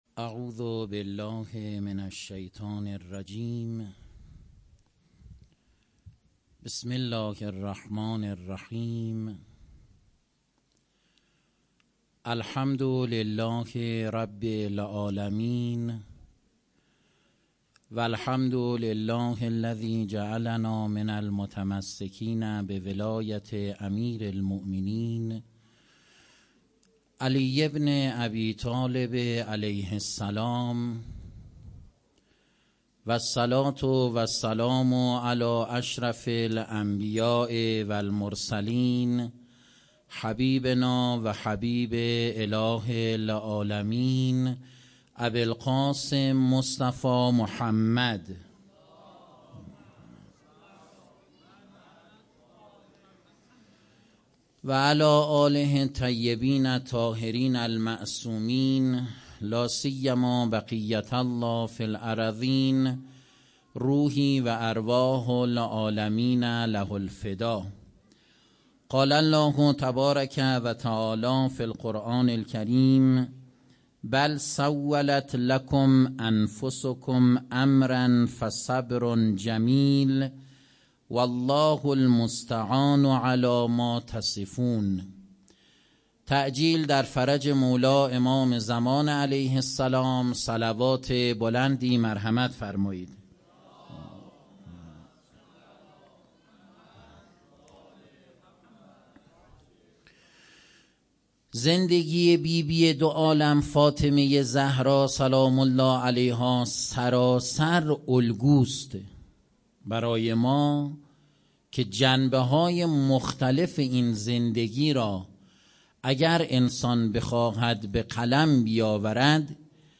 سخنرانی شب پنجم فاطمیه